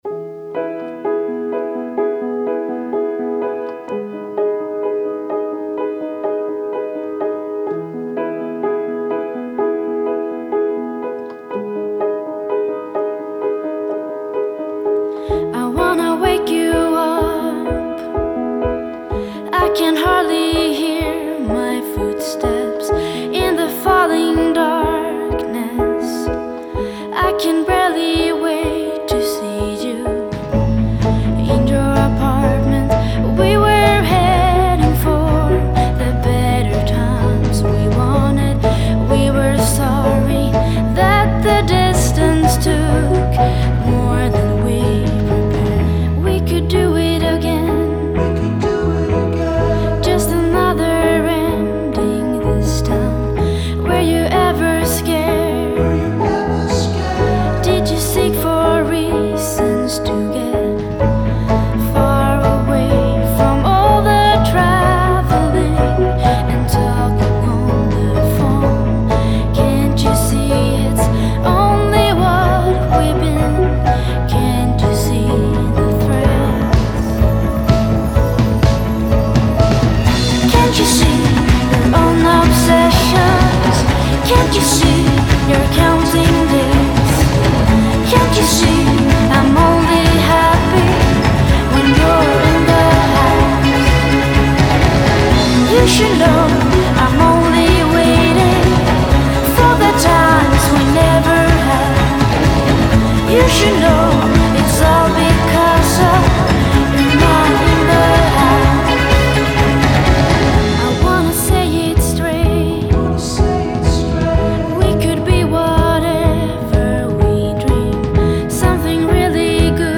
Swedish singer and musician.
Genre: Indie, Pop